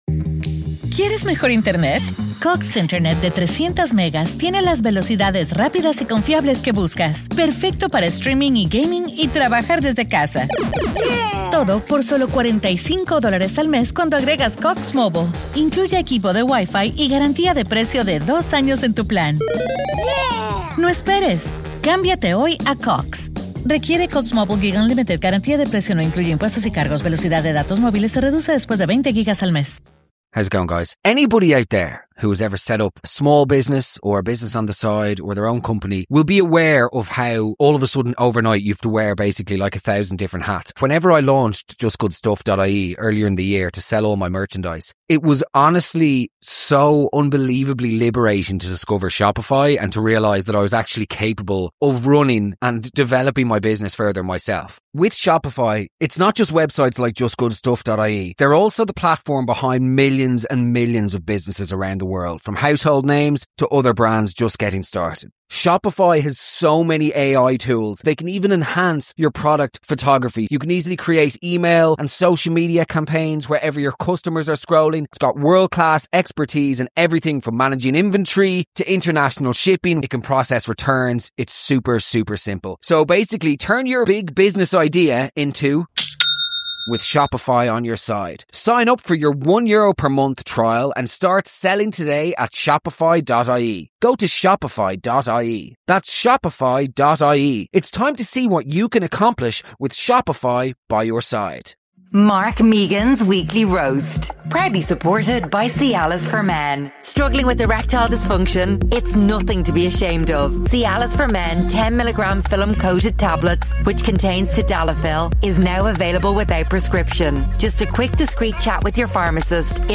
Live from Gran Canaria, welcome to the exclusive guide to all inclusive hotels!!